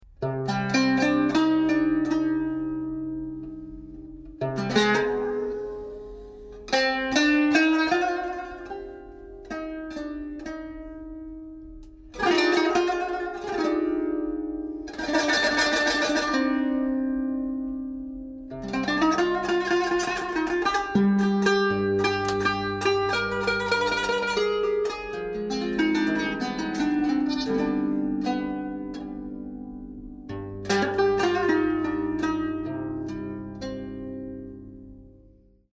Traditional Syrian Instrument
Qanun
Audio file of the Qanun
Qanun_iq76qsby.mp3